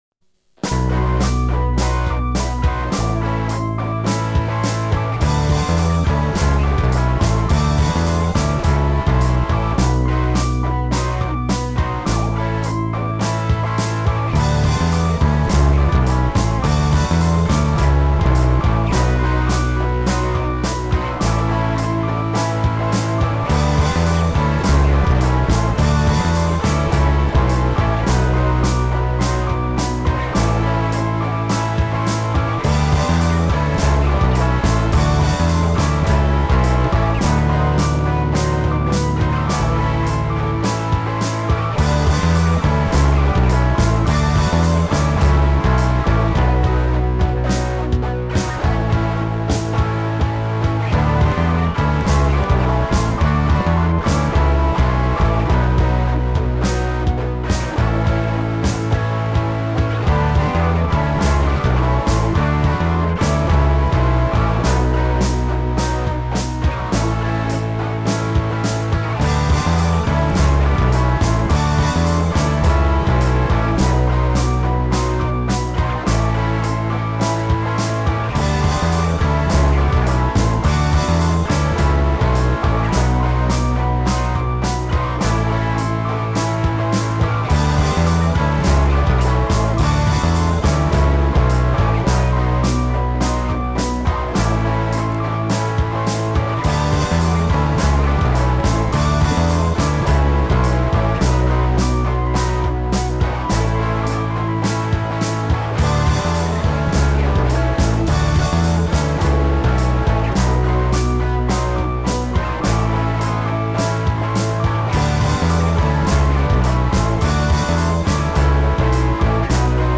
self remix album
BOSSのドラムマシン大活躍。